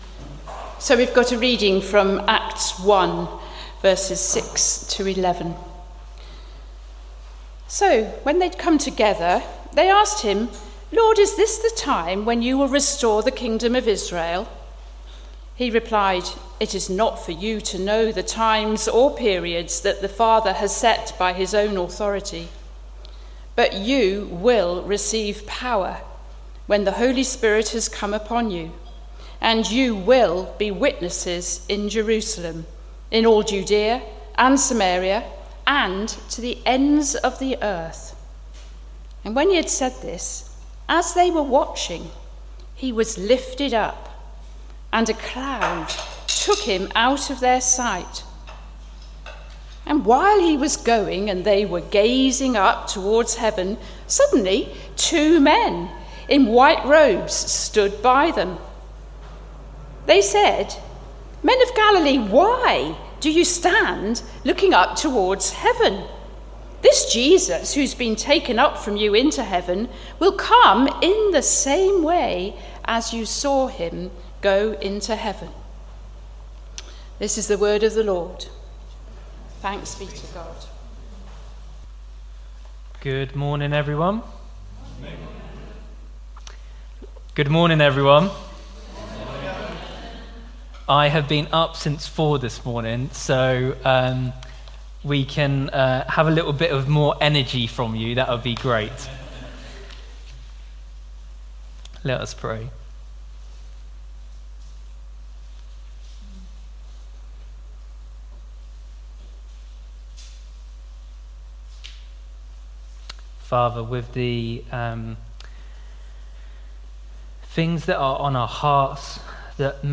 Sermon 19th May 2024 11am gathering
We have recorded our talk in case you missed it or want to listen again.